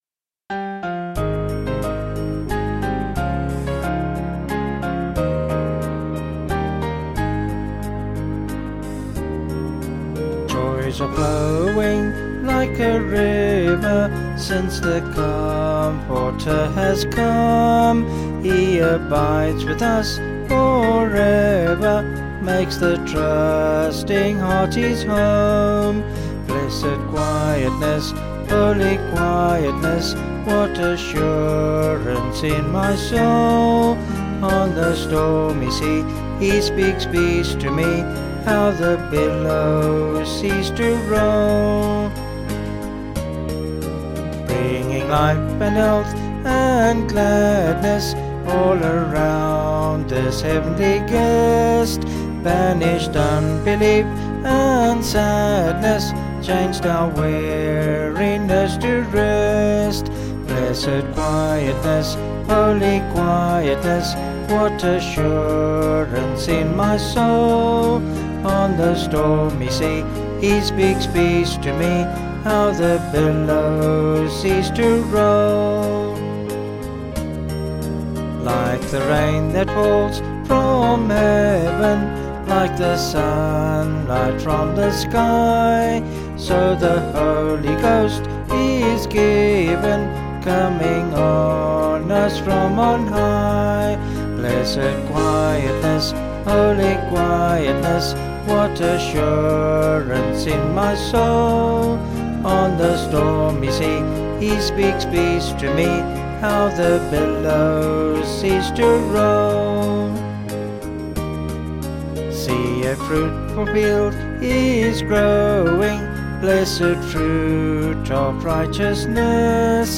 Vocals and Band   264.3kb Sung Lyrics